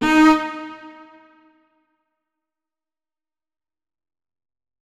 5caee9fba5 Divergent / mods / Hideout Furniture / gamedata / sounds / interface / keyboard / strings / notes-39.ogg 52 KiB (Stored with Git LFS) Raw History